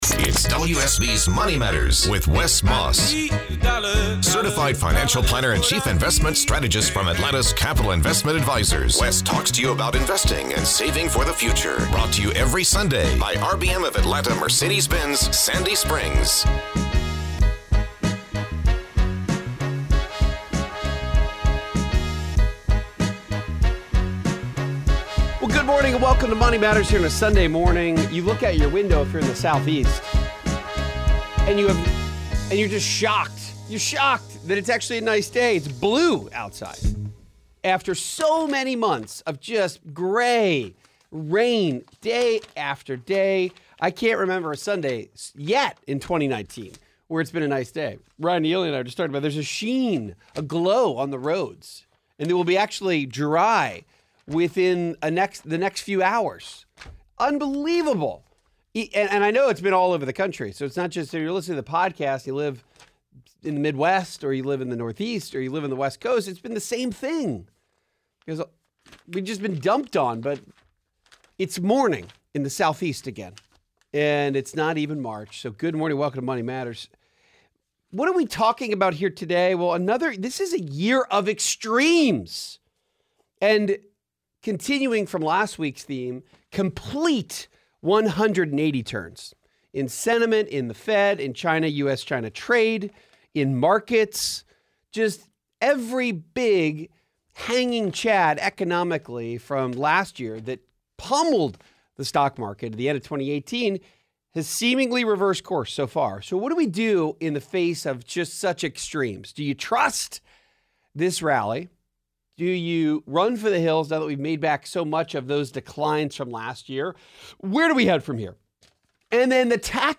There are multiple phone calls on this episode of MM ranging from dividend-paying stocks to how to find a financial advisor you can trust.